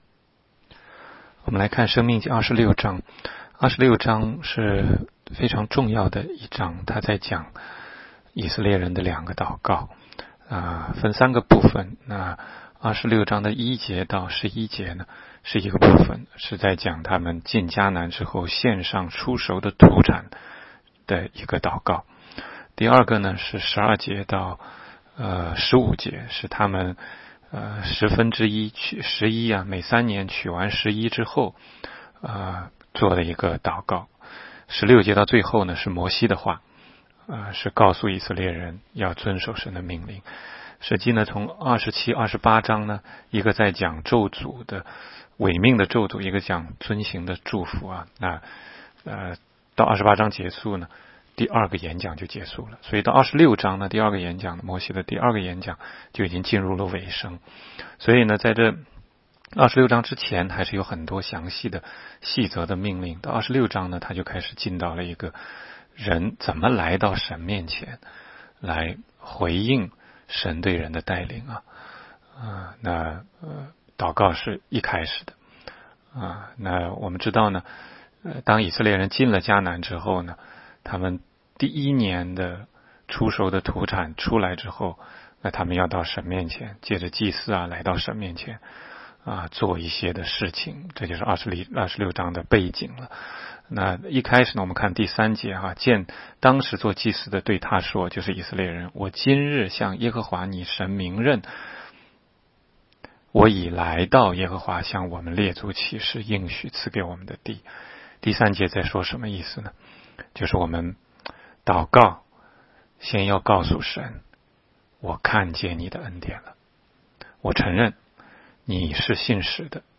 16街讲道录音 - 每日读经-《申命记》26章